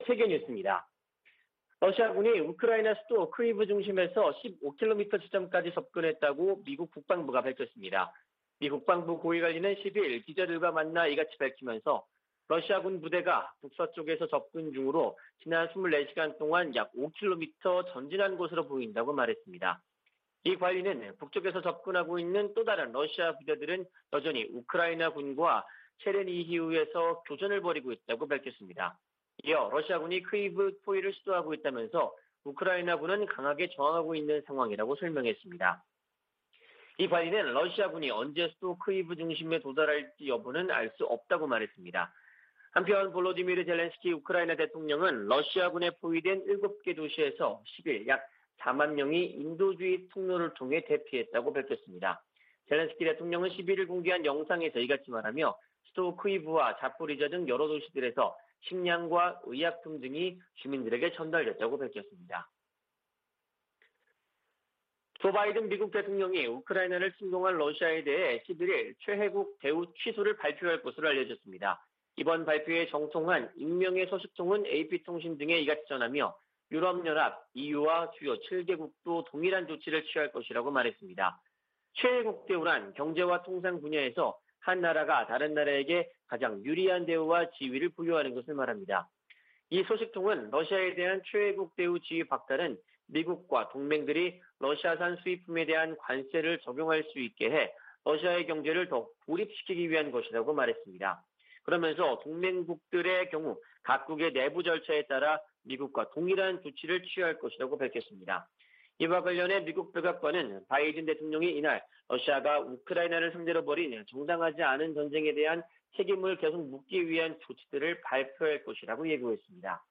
VOA 한국어 '출발 뉴스 쇼', 2022년 3월 12일 방송입니다. 미 국무부는 북한 핵·미사일 위협을 한국의 윤석열 차기 정부와 핵심 협력 사안으로 꼽았습니다. 미국 주요 언론은 한국에 보수 정부가 들어서면 대북정책, 미한동맹, 대중국 정책 등에서 큰 변화가 있을 것으로 전망했습니다. 북한이 폭파했던 풍계리 핵실험장을 복구하고 금강산에 있는 한국 측 시설을 철거하는 움직임이 포착된 것으로 알려졌습니다.